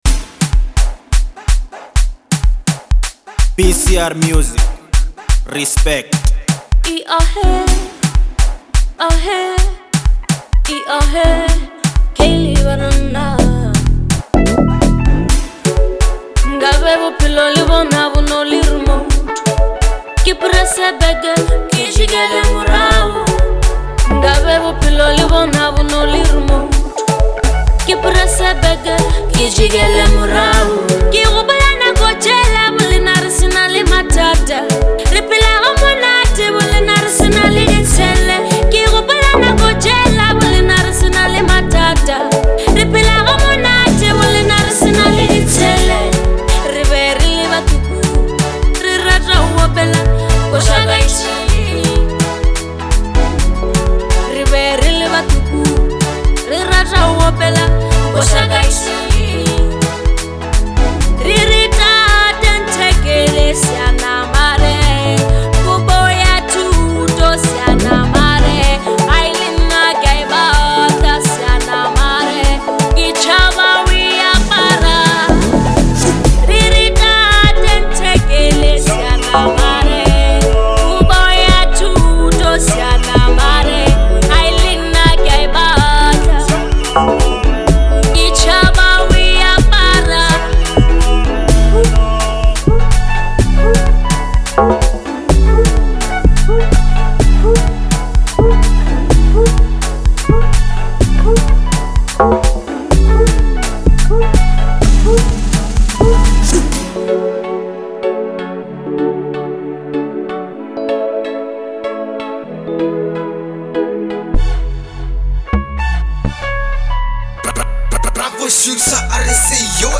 powerful and deeply emotional single
With her soulful vocals and raw storytelling
• Emotional depth blended with hard-hitting beats